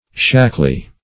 shackly - definition of shackly - synonyms, pronunciation, spelling from Free Dictionary Search Result for " shackly" : The Collaborative International Dictionary of English v.0.48: Shackly \Shack"ly\, a. Shaky; rickety.